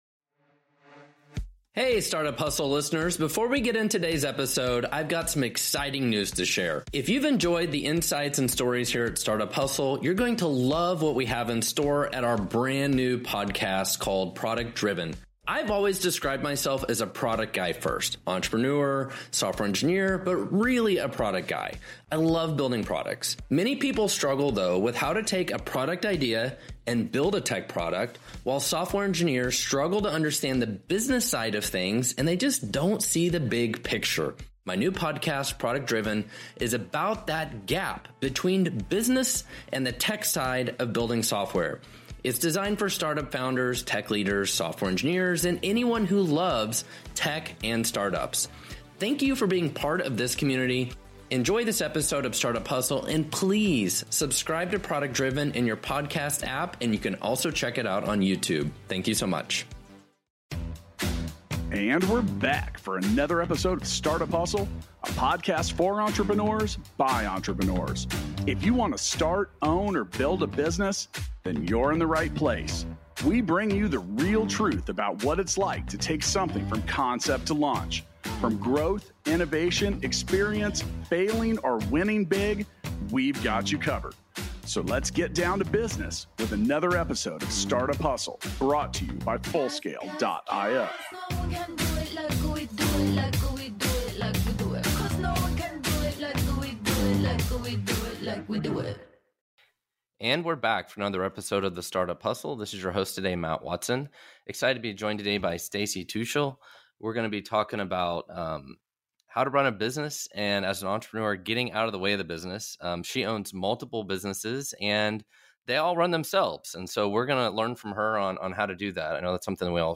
for a conversation around creating more efficient remote teams. Hear about strategies and best practices that actually work well for remote teams and that you can use in and for your own organization right away.